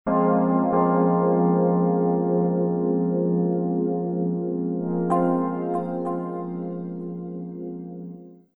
I worked a lot on the keyboard sound by programming the old Korg Wavestation. Today it shows a bit awkward to program, but hear what comes out by just pressing two keys and hold:
virus-keybWaveseq.mp3